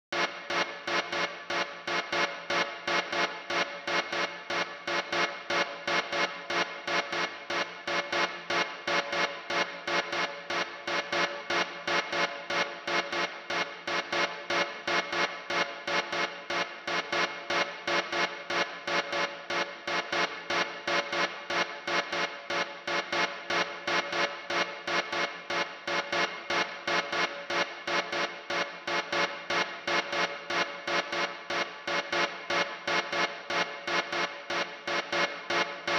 These are the chord sounds I was then able to create by resampling small, textual parts from the granulator into the Ableton sampler.
chords-1.wav